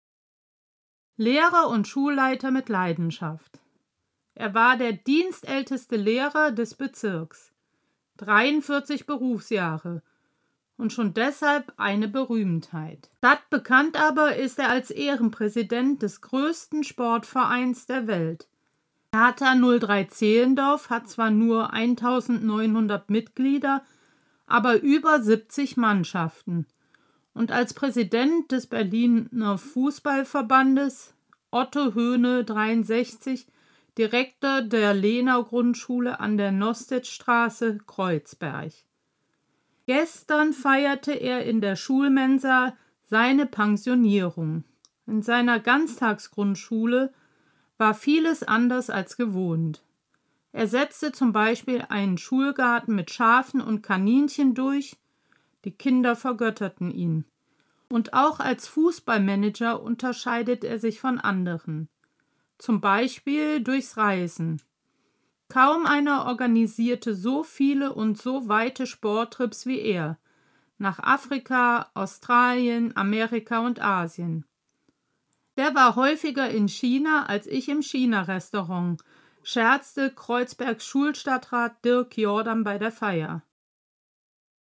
DAISY-Hörbuch